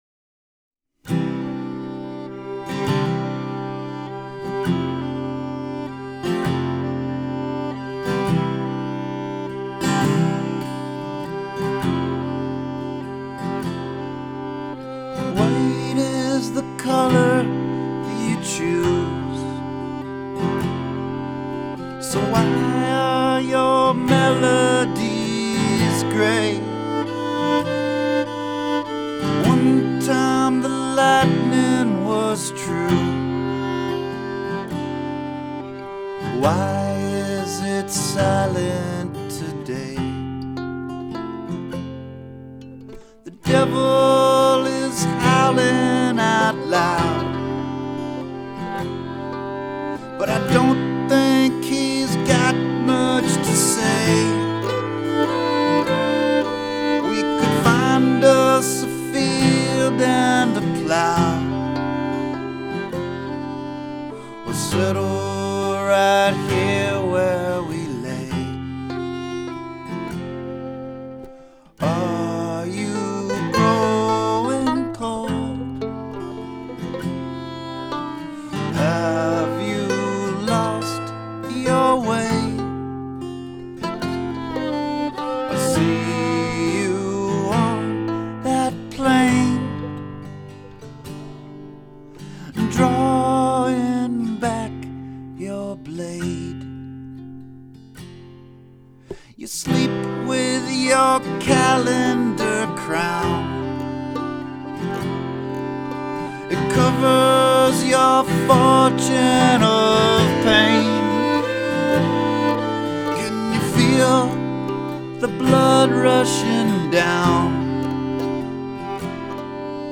Guitar and vocals
Mandolin, fiddle, and vocals